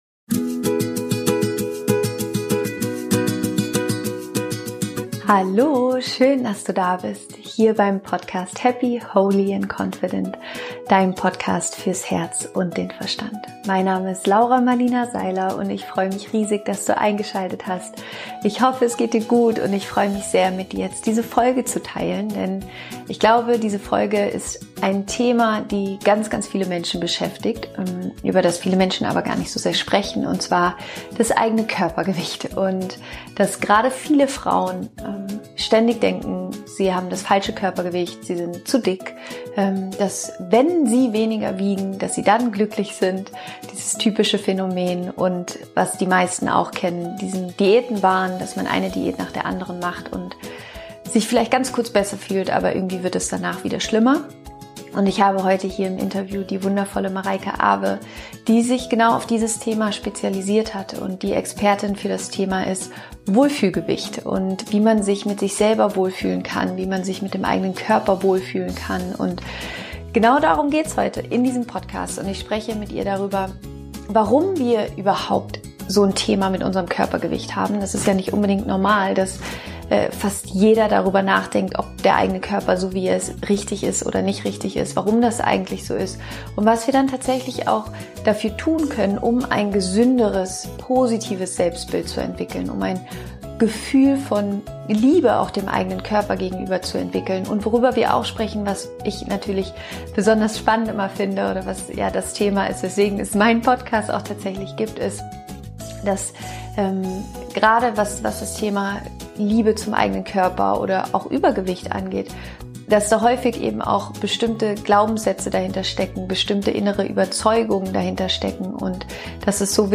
Nie wieder Diäten. Wie du dich mit deinem Körper wohlfühlst - Interview